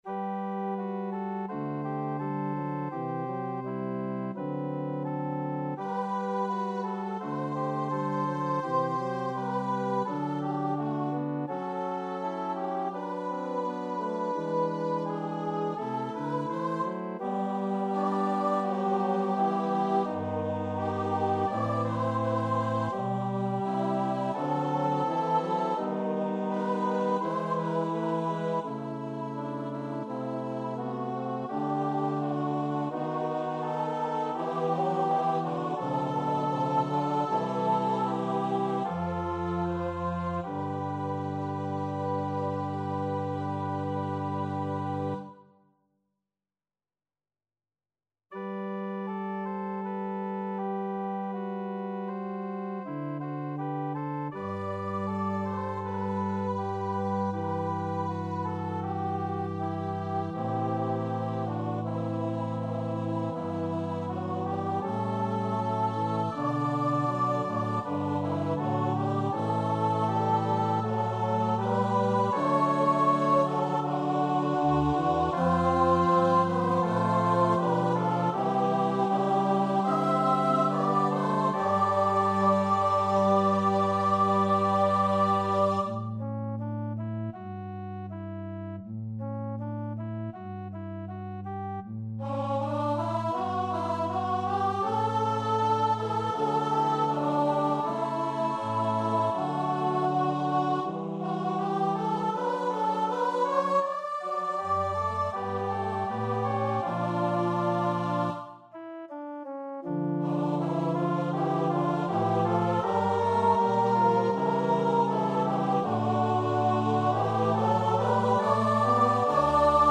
Number of voices: 2vv Voicing: Flexible Genre: Sacred, Communion Service
Language: English Instruments: Organ